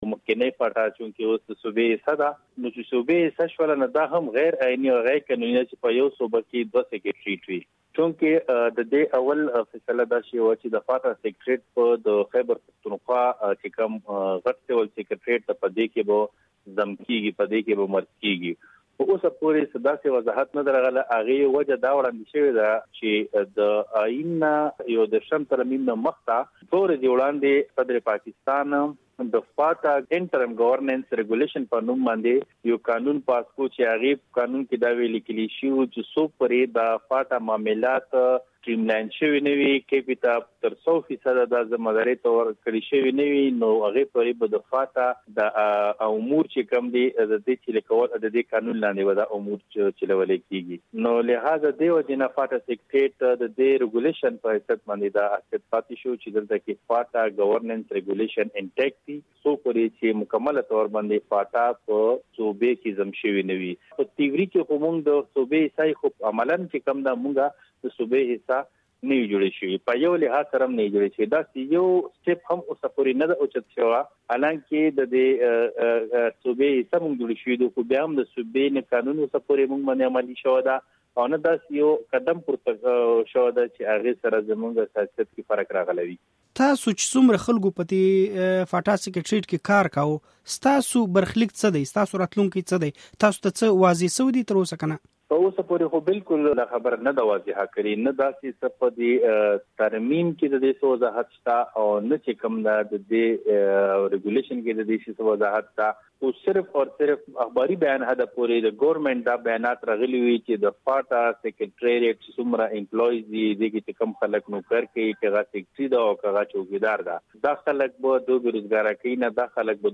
له نوموړي څخه یې د مرکې په پیل کې پوښتلي چې تر انظمام وروسته اوس د فاټا سیکریټرېټ حثیت څه پاته شوی دی؟